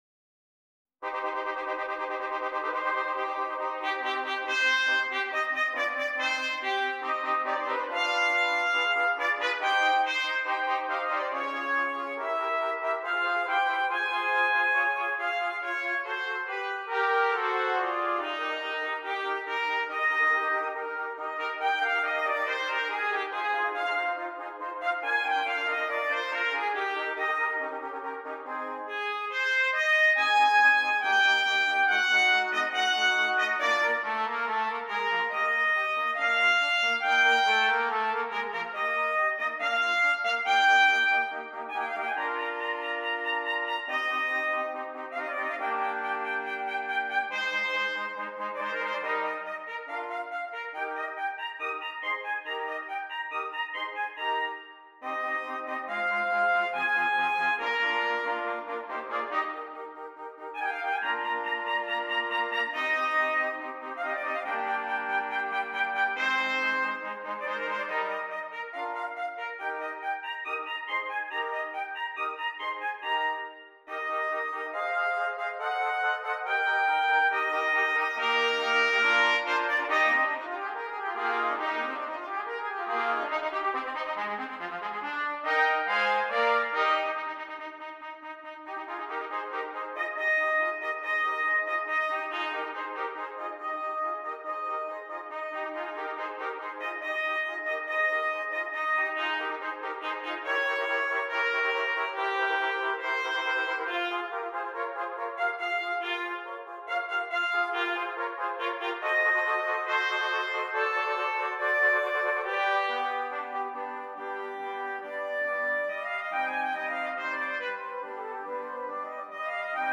5 Trumpets